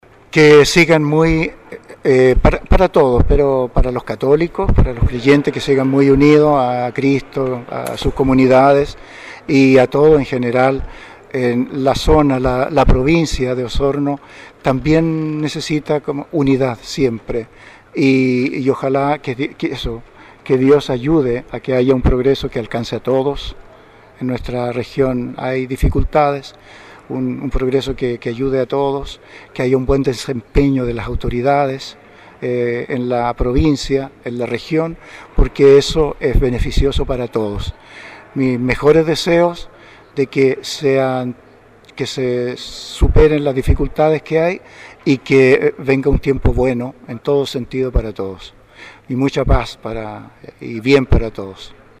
Con la Iglesia Catedral San Mateo completa de fieles, en la tarde de ayer se realizó la Misa-Acción de Gracias por su servicio como pastor de la Diócesisde Monseñor Jorge Concha Cayuqueo.